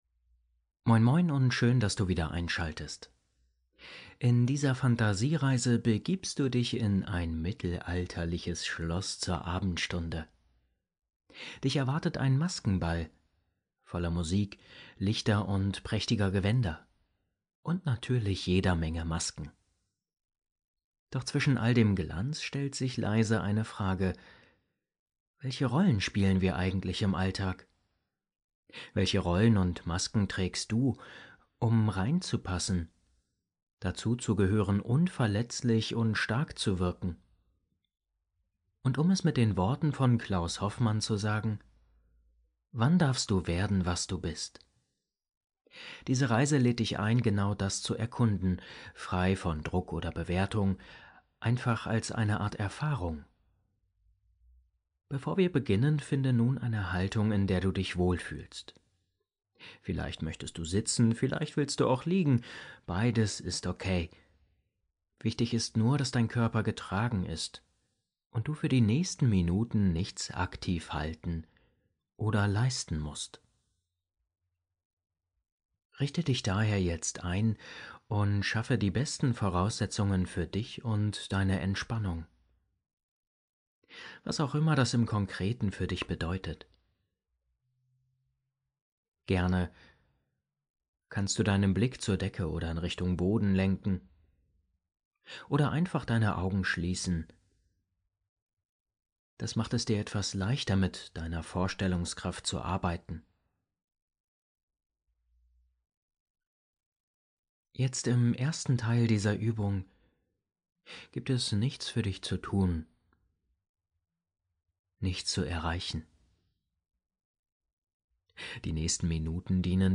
Geführte Fantasiereise - Zwischen Sein und Rolle ~ Entspannungshelden – Meditationen zum Einschlafen, Traumreisen & Entspannung Podcast